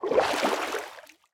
sounds / liquid / swim16.ogg
swim16.ogg